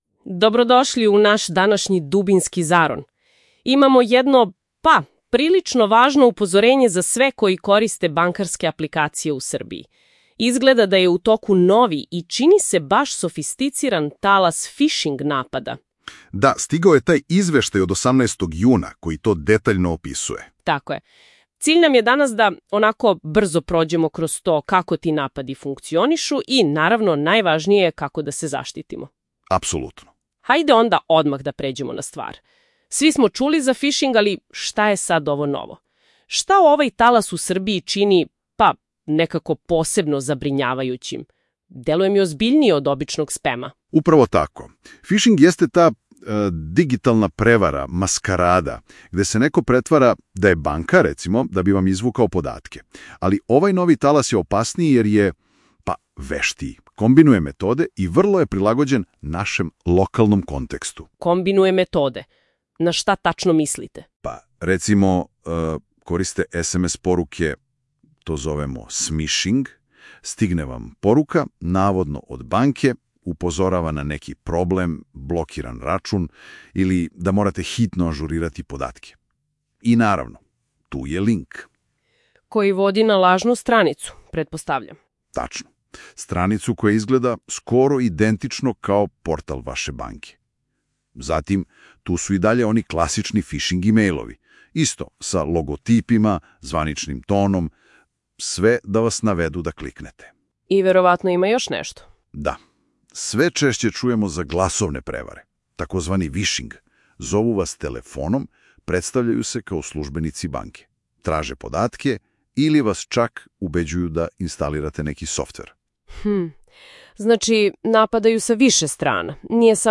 Audio recenzija teksta